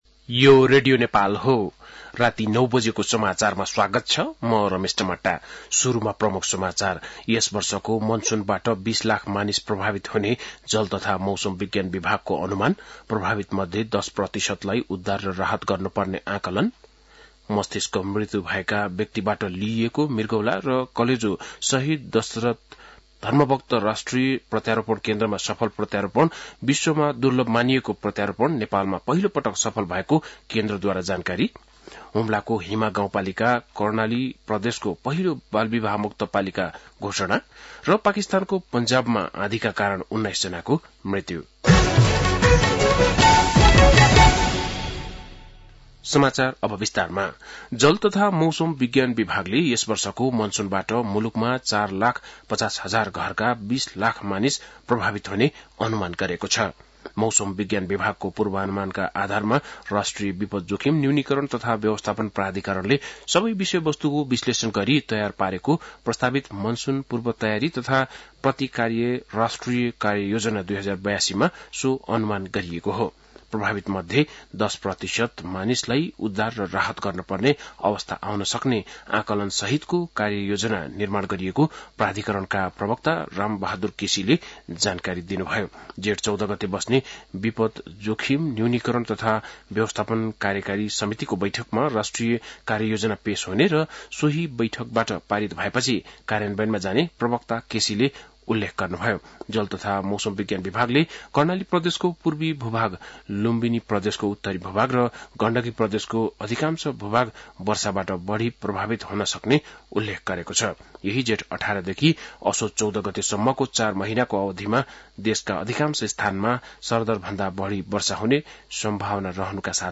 बेलुकी ९ बजेको नेपाली समाचार : ११ जेठ , २०८२
9-pm-nepali-news-.mp3